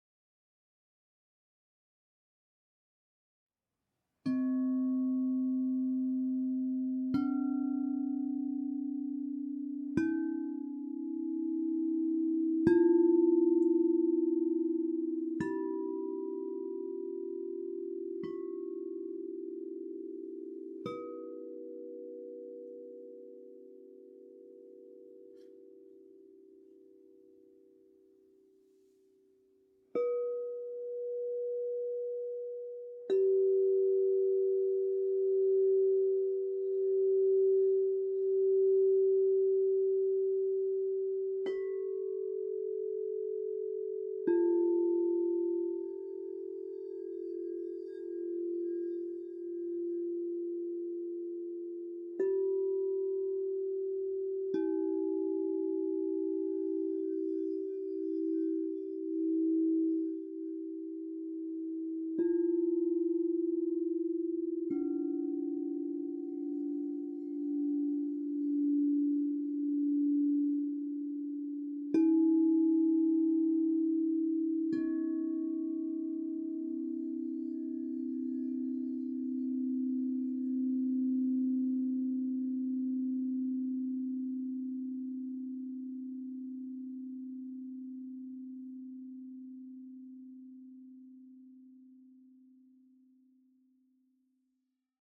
Meinl Sonic Energy 7-piece Marble Crystal Singing Bowl Chakra Set, B4, A4, G4, F4, E4, D4, C4, 432 Hz (MCSBSETCHA)